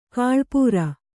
♪ kāḷpūra